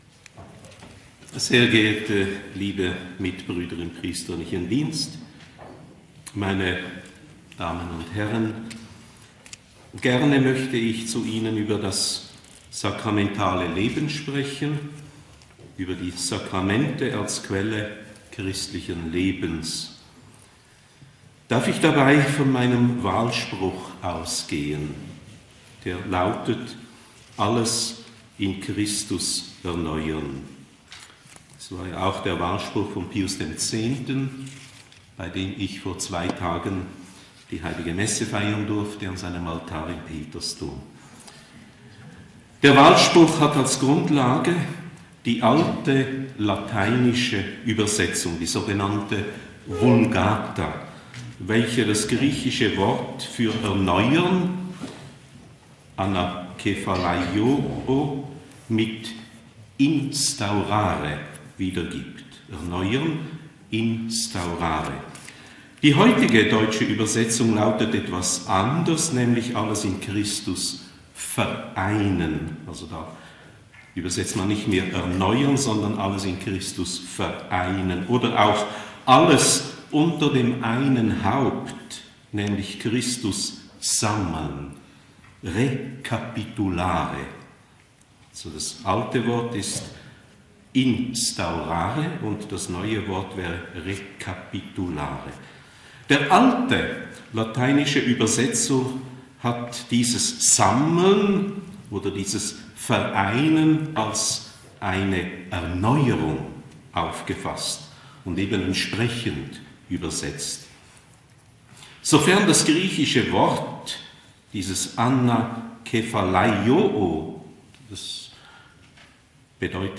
Bischof Vitus Huonder - Die Sakramente - Katholische Predigten & Vorträge